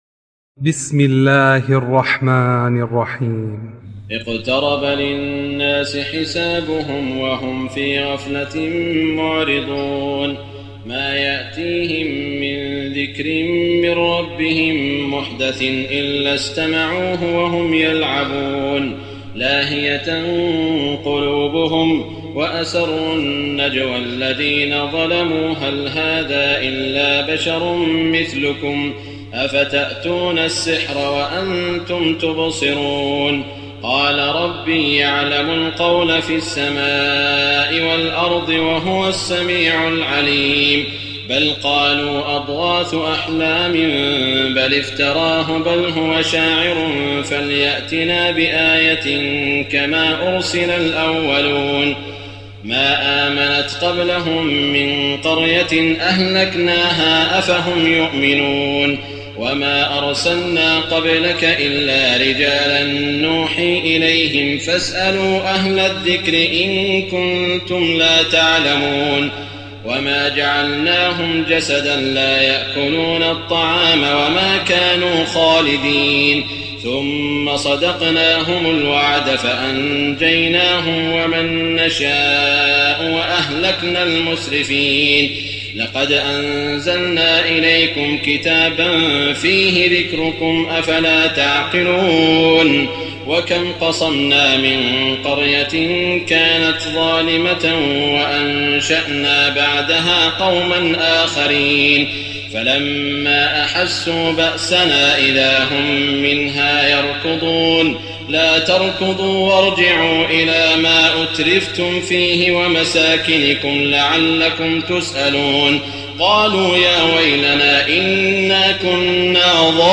تراويح الليلة السادسة عشر رمضان 1419هـ سورة الأنبياء كاملة Taraweeh 16 st night Ramadan 1419H from Surah Al-Anbiyaa > تراويح الحرم المكي عام 1419 🕋 > التراويح - تلاوات الحرمين